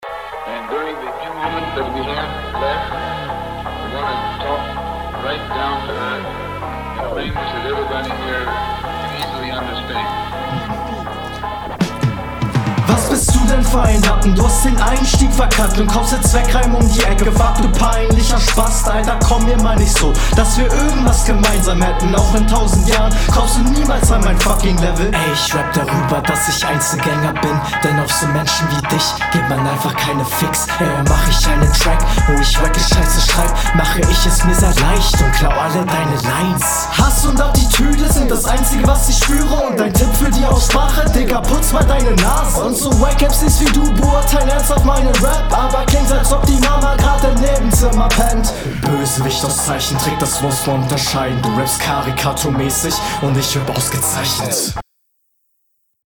flowlich hat das stabile momente, ich mag die grundlegende attitüde hier relativ gerne, ist aber …
schneid gerne beim nächsten mal den Anfang deiner Spur ab, rauscht mega derbe und man …